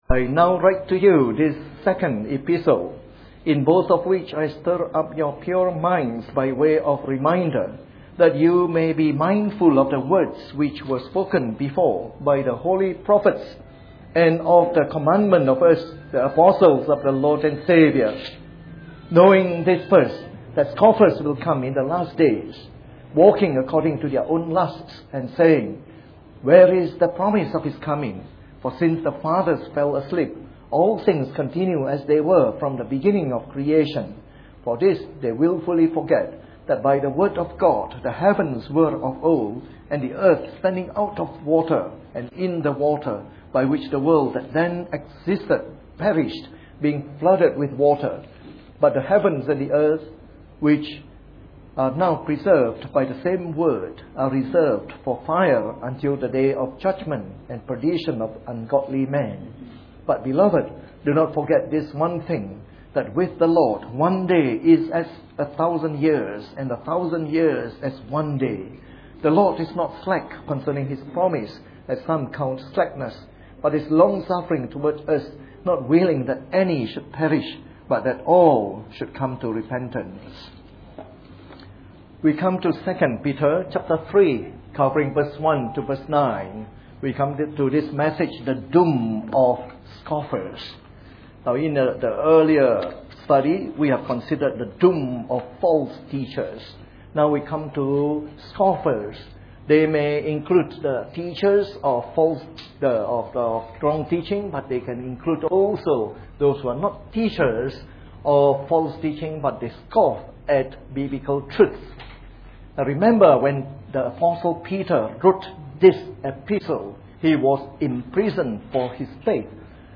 Part of our series on “The Epistles of Peter” delivered in the Evening Service.